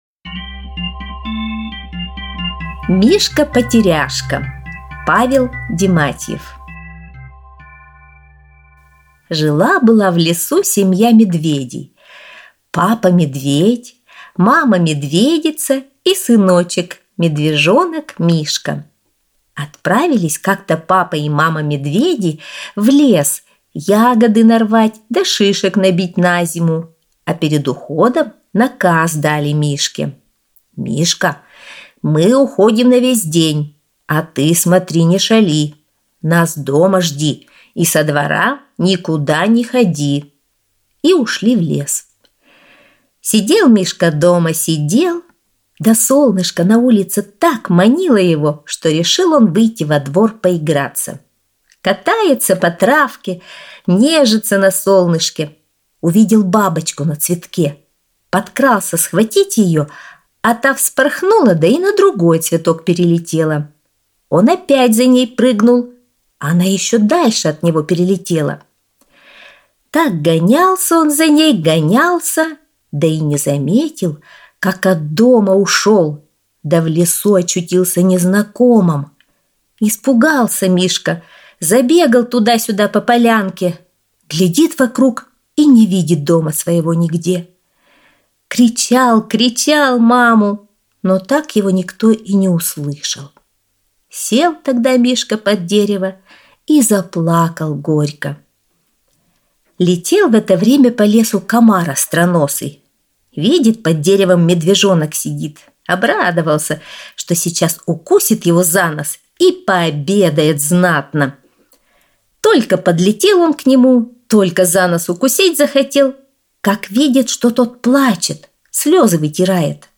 Аудиосказка «Мишка-потеряшка»